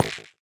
Minecraft Version Minecraft Version 1.21.5 Latest Release | Latest Snapshot 1.21.5 / assets / minecraft / sounds / block / nether_wood_hanging_sign / step4.ogg Compare With Compare With Latest Release | Latest Snapshot